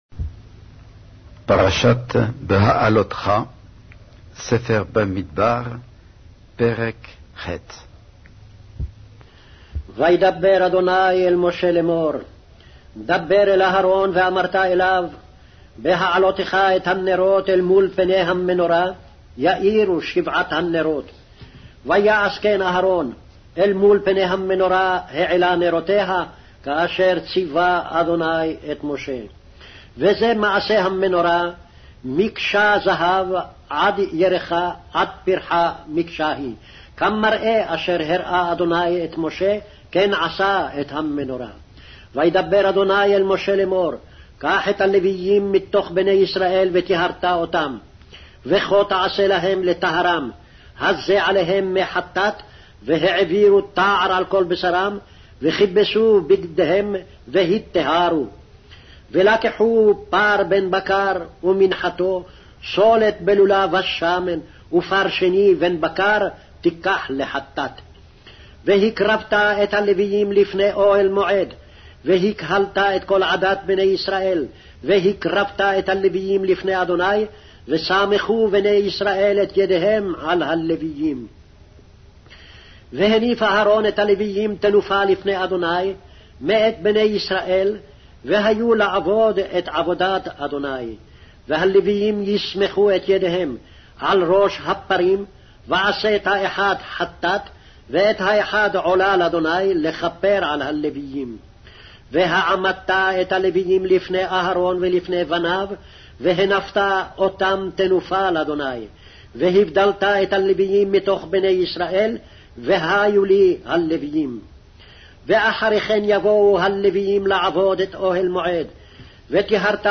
Hebrew Audio Bible - Numbers 18 in Net bible version